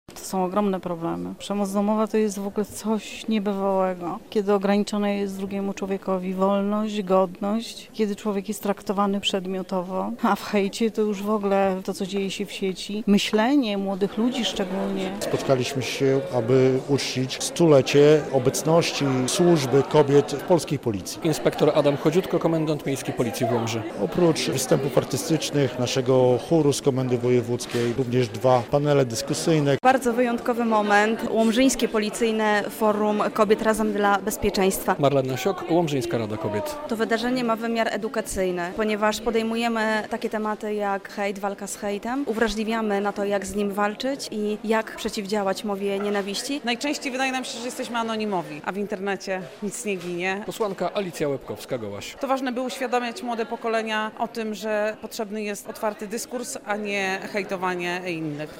Policyjne Forum Kobiet w Łomży - relacja